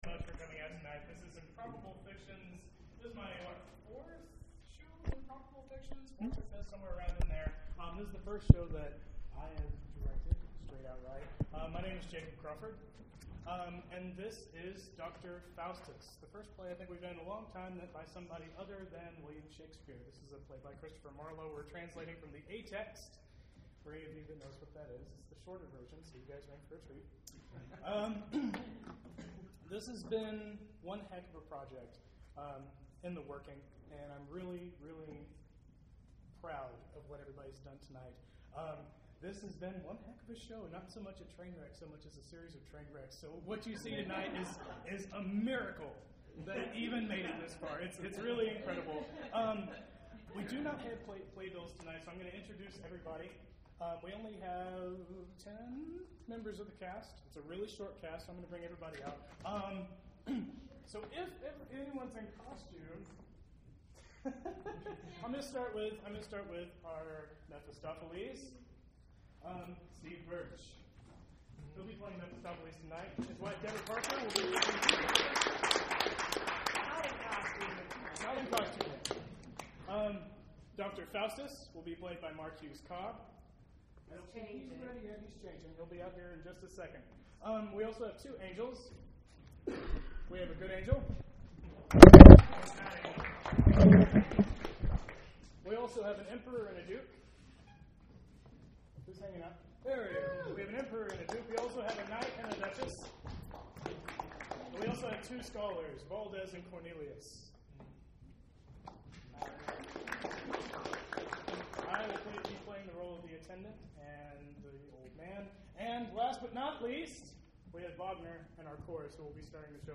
If you missed tonight's staged reading of Doctor Faustus, you can find the audio here.
Reblogged this on irrecollections. and commented: Here’s the audio from our staged reading of Doctor Faustus last week.
Feel free to skip my Kermit the Frog-sounding monologue at the beginning.